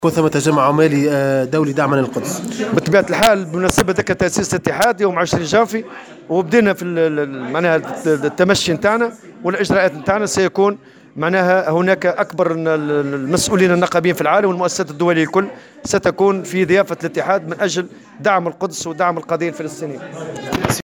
وقال في تصريح لمراسل "الجوهرة أف أم" على هامش زيارة أداها اليوم إلى سيدي بوزيد لاحياء ذكرى 17 ديسمبر 2010، إن الاتحاد بدأ استعداداته لهذا الحدث الدولي الذي سيشارك فيه كبار المسؤولين النقابيين في العالم بالإضافة إلى مؤسسات دولية ستكون في تونس لدعم القدس ودعم الفلسطينيين في دفاعهم عن القدس.